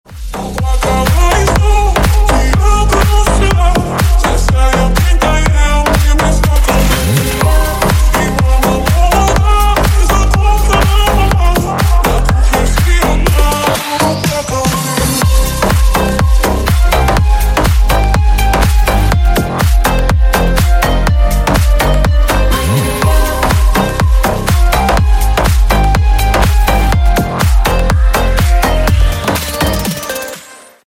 Клубные Рингтоны » # Громкие Рингтоны С Басами
Рингтоны Ремиксы » # Танцевальные Рингтоны